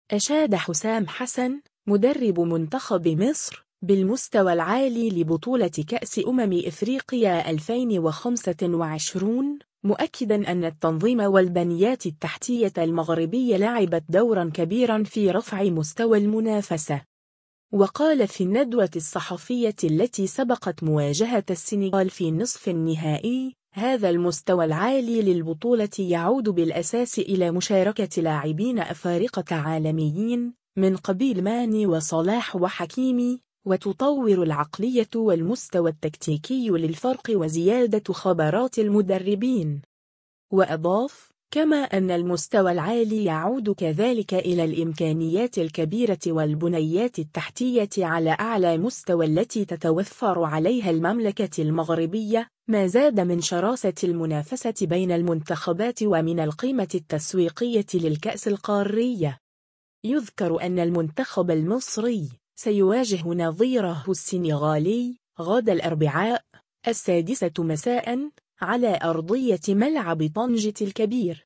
و قال في الندوة الصحفية التي سبقت مواجهة السنغال في نصف النهائي : “هذا المستوى العالي للبطولة يعود بالأساس إلى مشاركة لاعبين أفارقة عالميين، من قبيل ماني وصلاح و حكيمي، و تطور العقلية و المستوى التكتيكي للفرق و زيادة خبرات المدربين”.